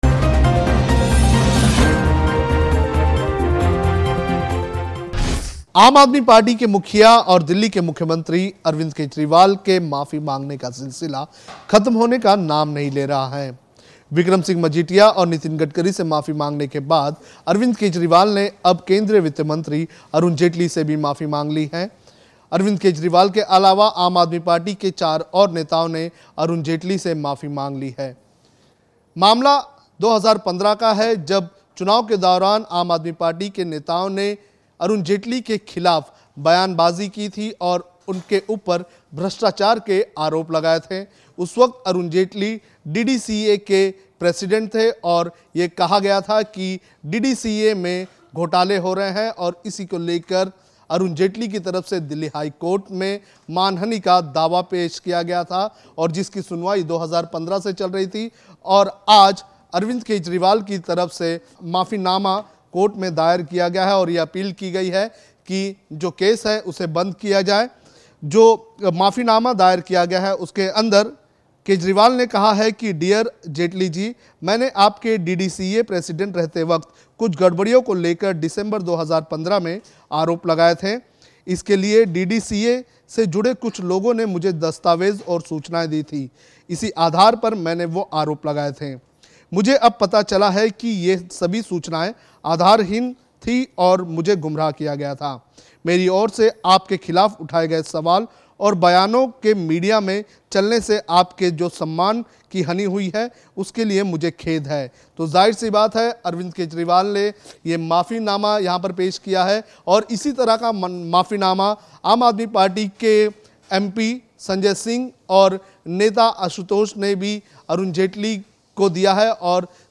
News Report / आखिर क्यूँ केजरीवाल को खाने पड़ रही है अपने मुह की, माफ़ी के बाद माफ़ी